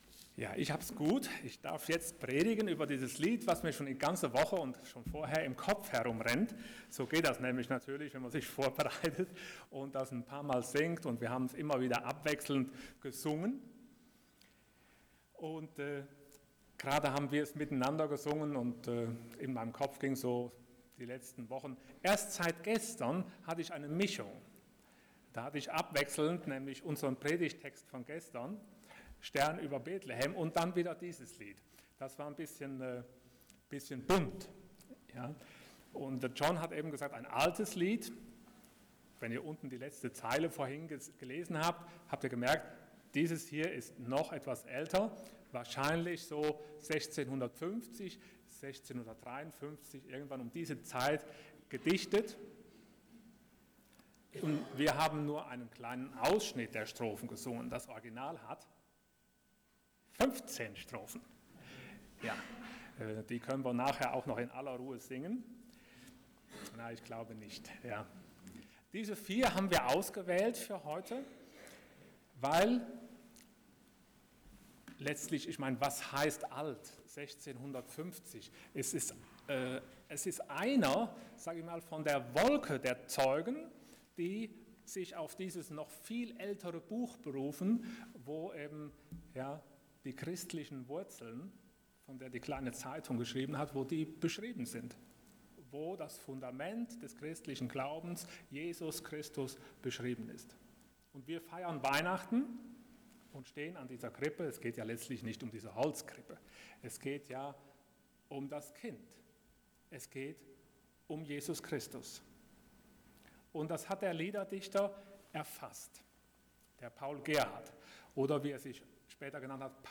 24.12.2018 Ich steh an deiner Krippe hier Prediger